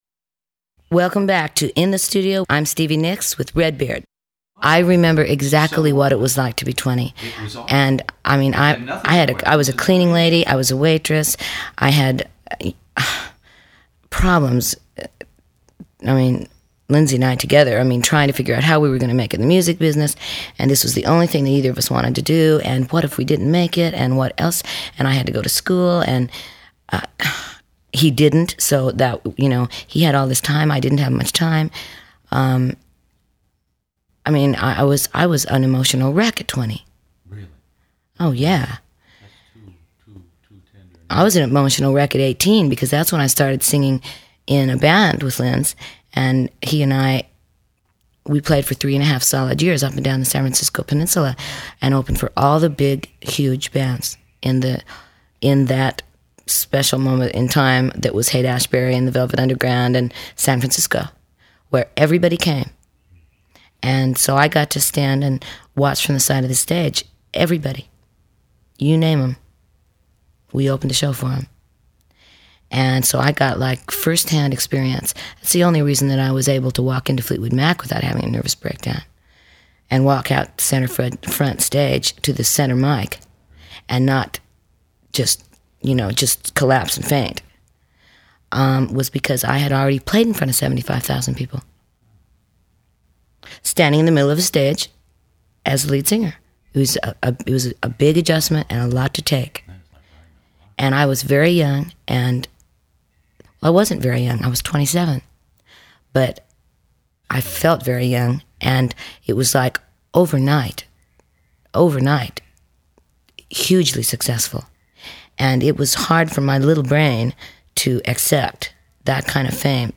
Buckingham Nicks- Lindsey Buckingham, Stevie Nicks Rare Interview
One of the world's largest classic rock interview archives, from ACDC to ZZ Top, by award-winning radio personality Redbeard.